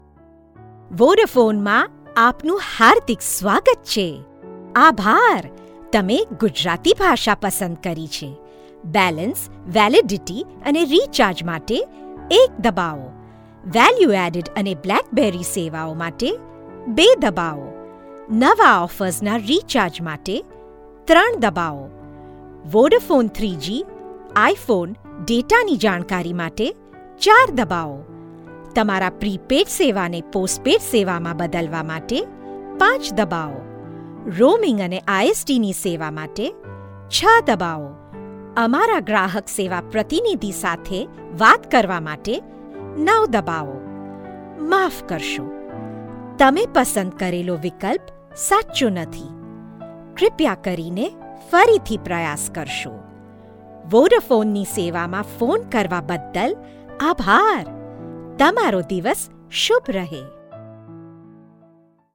Clear diction and speech. Correct pronunciation, soft, warm, fun, sarcastic. Can modulate different characters.
Sprechprobe: eLearning (Muttersprache):